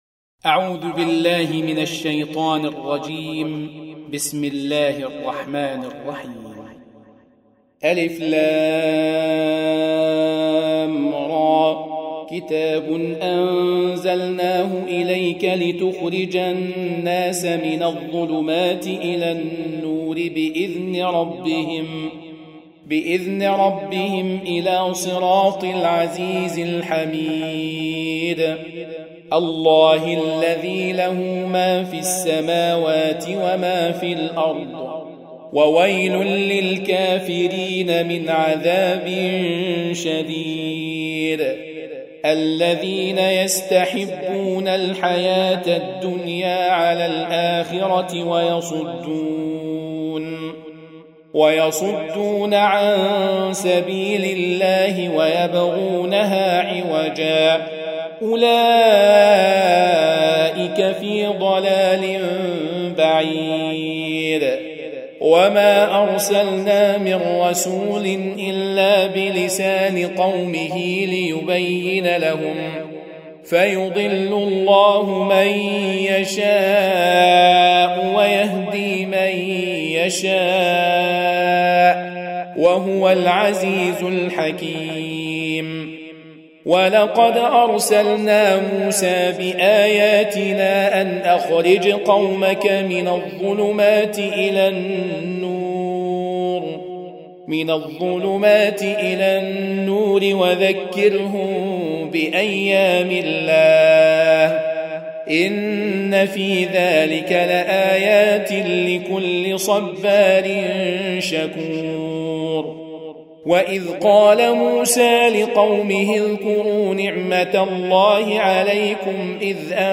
Audio Quran Tarteel Recitation
Surah Sequence تتابع السورة Download Surah حمّل السورة Reciting Murattalah Audio for 14. Surah Ibrah�m سورة إبراهيم N.B *Surah Includes Al-Basmalah Reciters Sequents تتابع التلاوات Reciters Repeats تكرار التلاوات